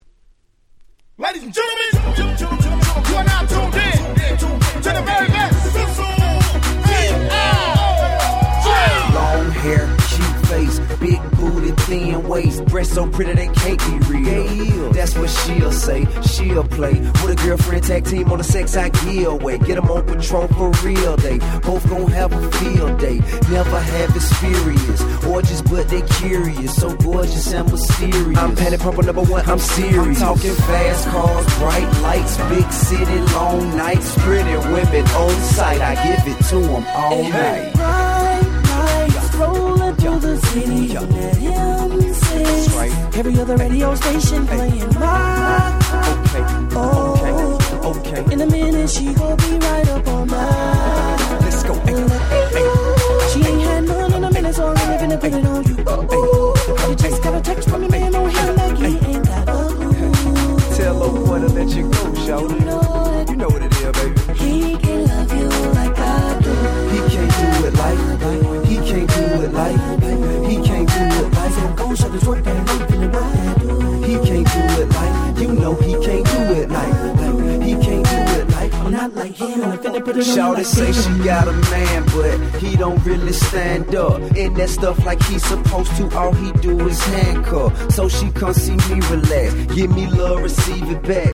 08' Nice Southern Hip Hop !!